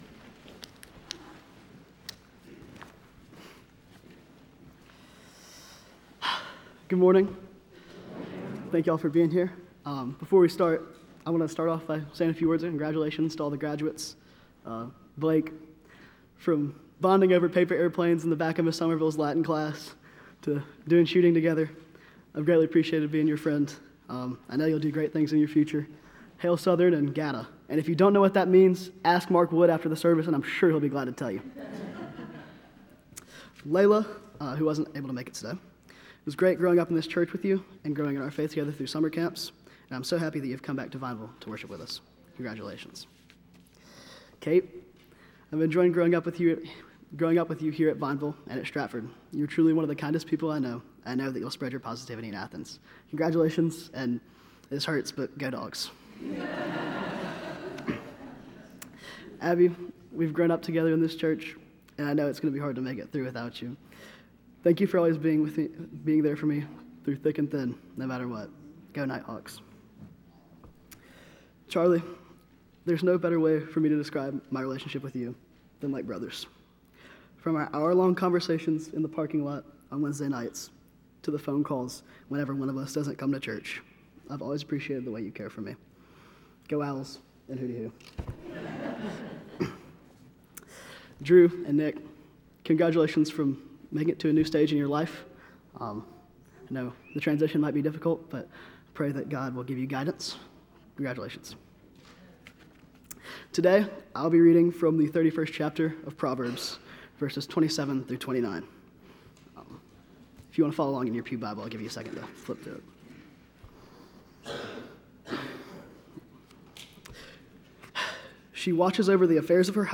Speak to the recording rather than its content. Youth Led Service